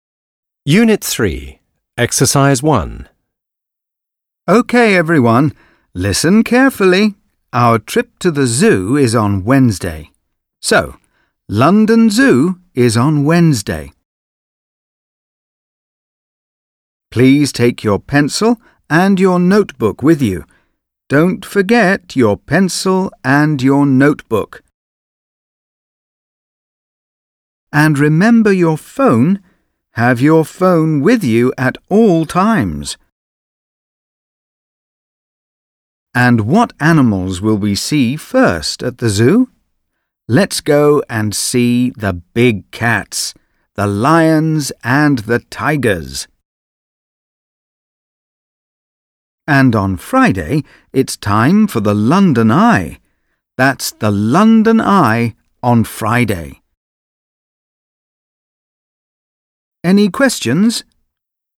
Kuuntele opettajan retkiohjeet luokalleen (nauhuri on yläpuolella).